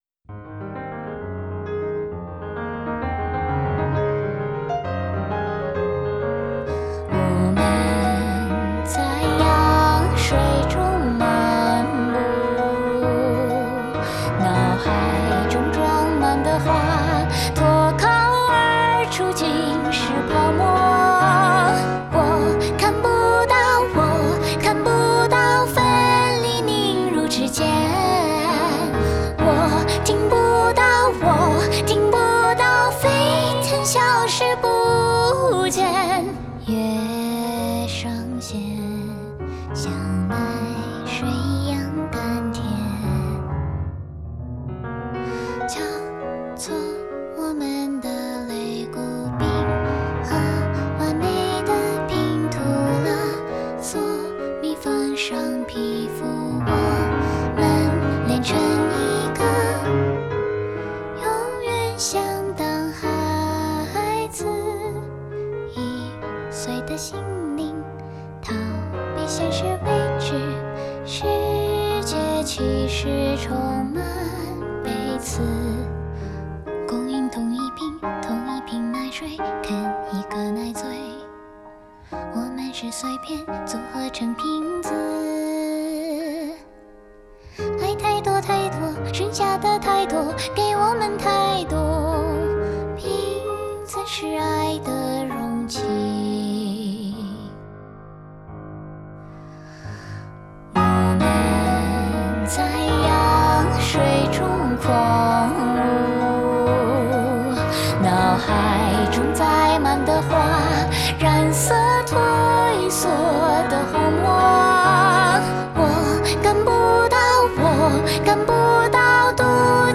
Vocal+Piano Unplugged